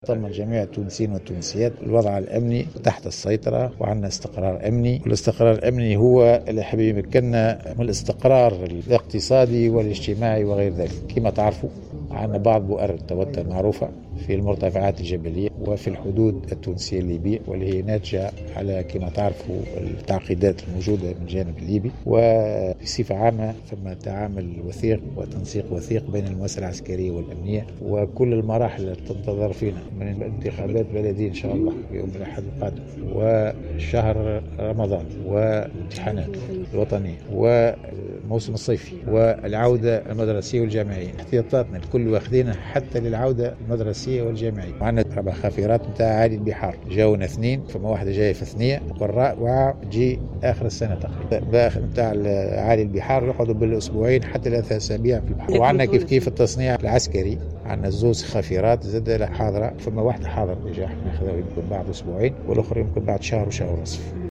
وأعلن الزبيدي، في تصريح لمراسلة الجوهرة أف أم، على هامش إفتتاح المؤتمر الخامس للمجموعة الإقليمية العربية للطب العسكري، أن الأسطول العسكري التونسي سيتعزز في غضون 4 أشهر بأربع خافرات جديدة وبدفعة ثانية من المروحيات، بالتوازي مع عمليات التصنيع التي تتواصل على قدم وساق في انتظار استكمال خافرة جديدة سيقع تسلمها خلال شهر ونصف من اليوم.